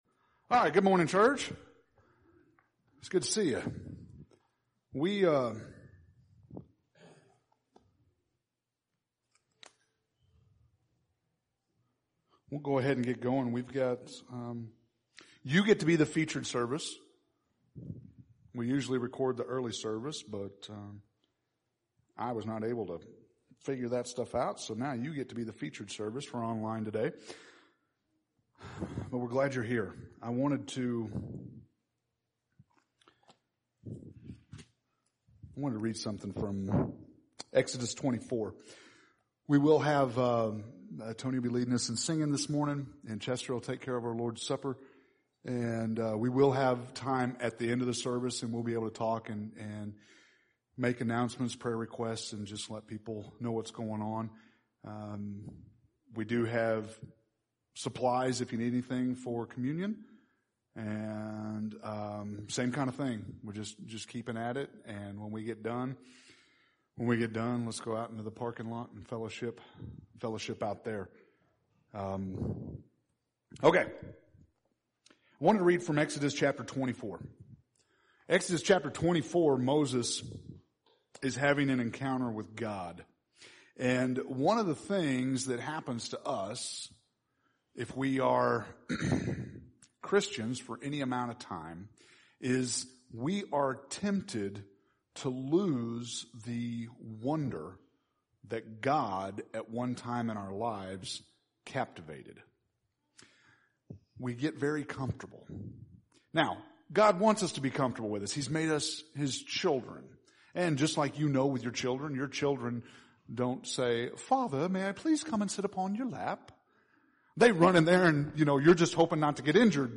June 21st – Sermons